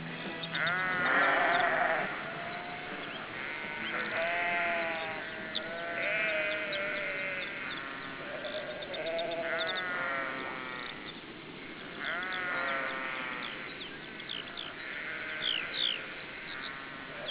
Schafsounds
Wiesensoundtrack:   didellmöhdadbööhhla     (wav 136 KB)
sheep1.wav